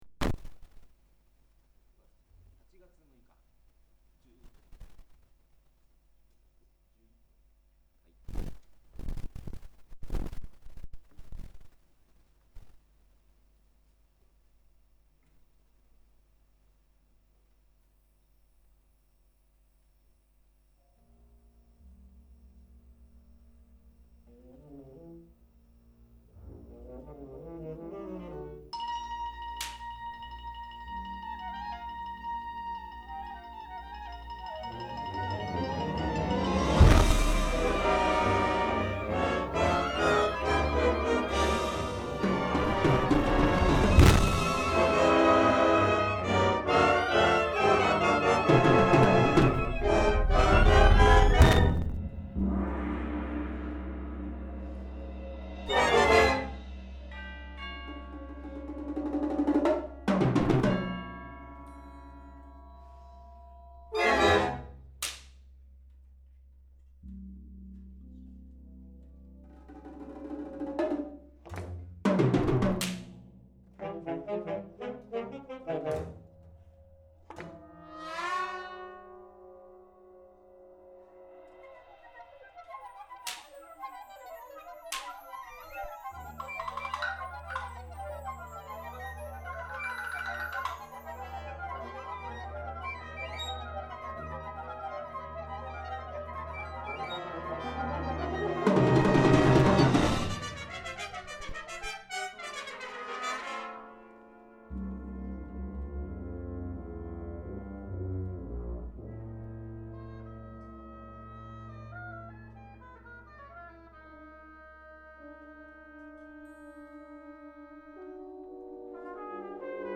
ジャンル: 吹奏楽